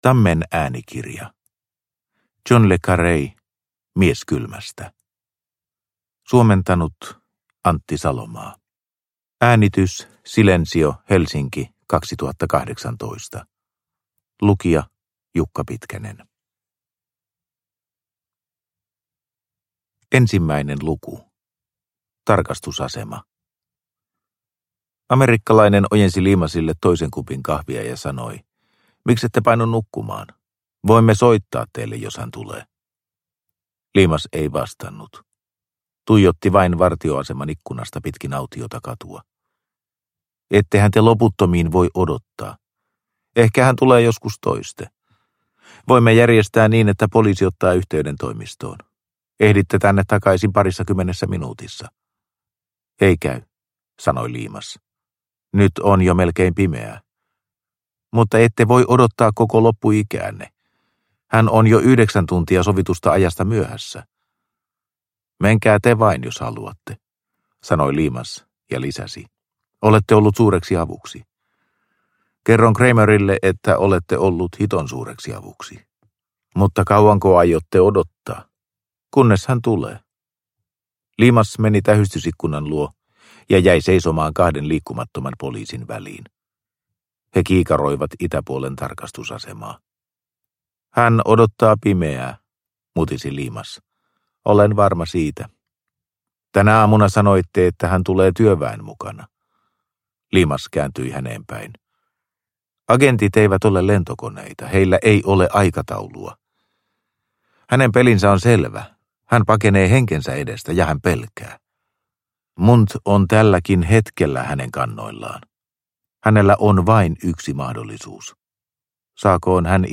Mies kylmästä – Ljudbok – Laddas ner